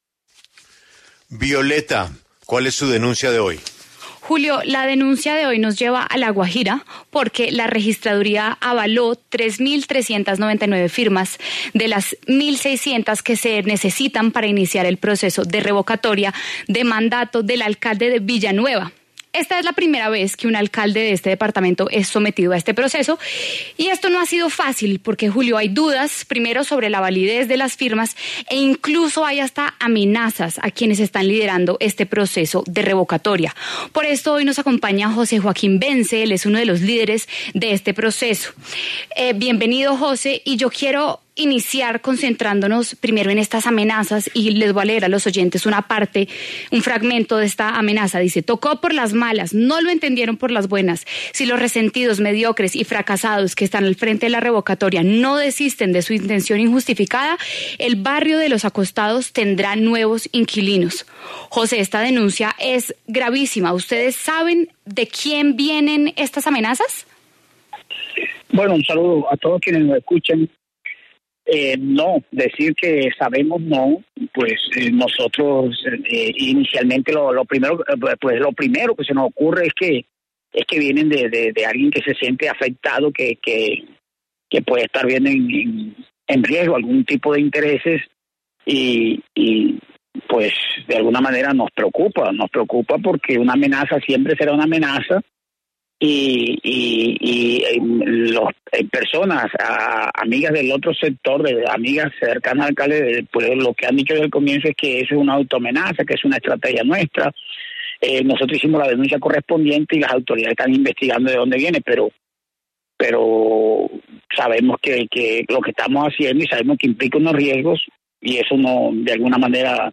En diálogo con La W, Carlos Alberto Barros, alcalde de Villanueva, resaltó que existen presuntas irregularidades en el proceso de revocatoria en su contra.